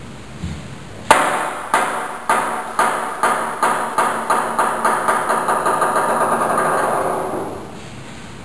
botes.
bounce.wav